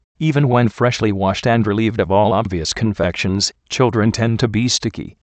Location: USA